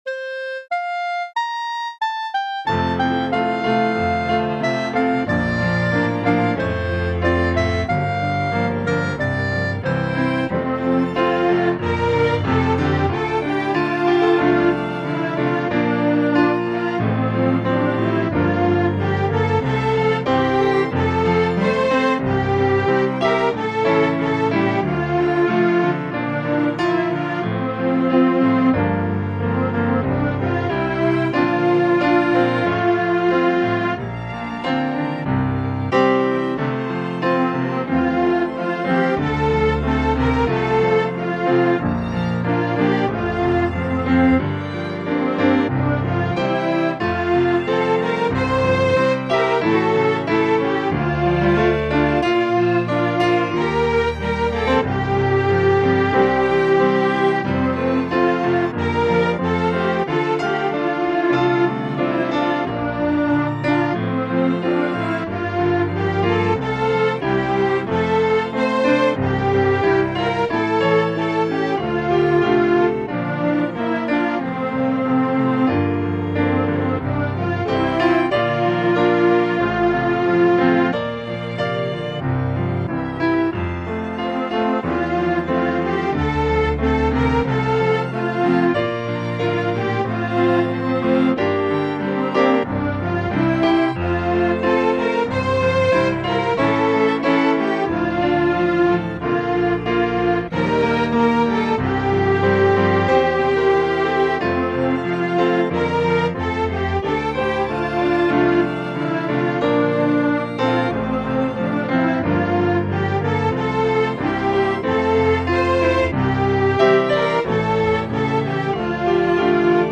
choir piece